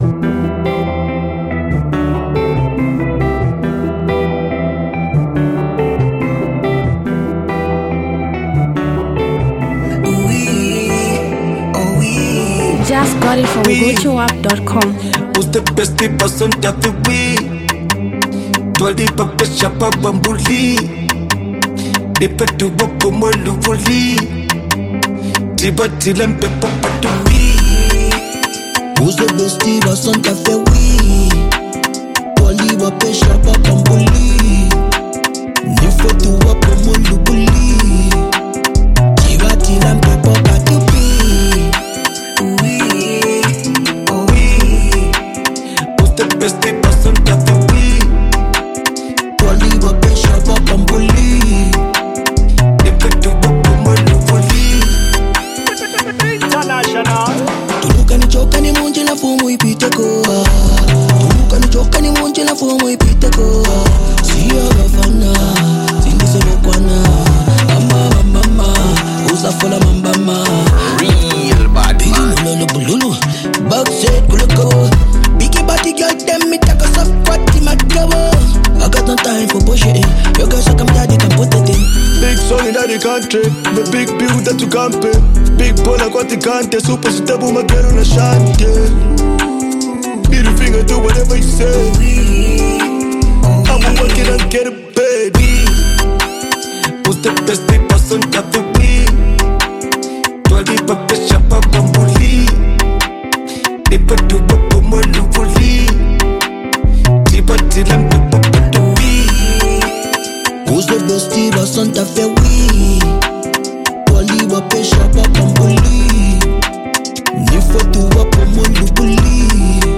street vibing genre